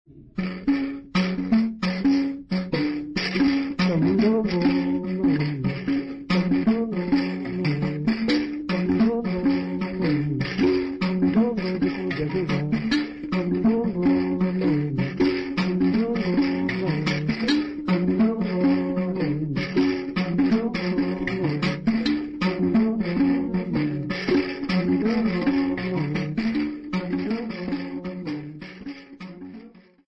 Sambiu church music workshop participants
Sacred music Namibia
Mbira music Namibia
Africa Namibia Sambiu mission, Okavango sx
field recordings
Church song accompanied by the mbira type instrument sisanti and indingo played at both lower and upper key.